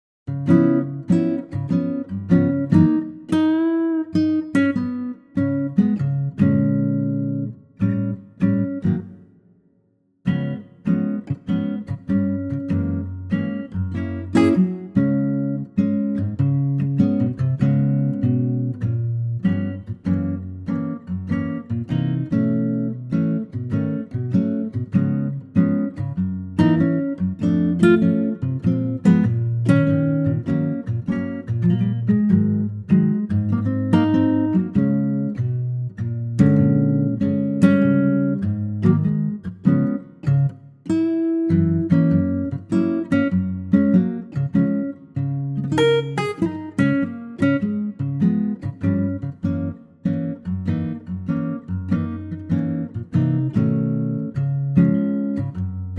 Db Acoustic Guitar
key - Db - vocal range - Db to Ab